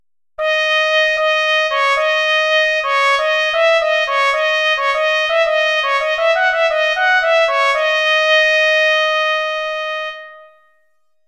Unison Rhythm, mm, 197-200 (MIDI audio file) (w/click)           Unison Rhythm, mm. 273-276 (MIDI audio file) (w/click)